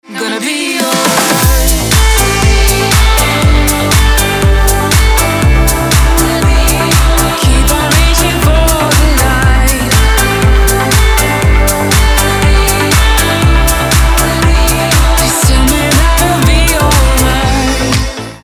• Качество: 320, Stereo
громкие
женский вокал
dance
house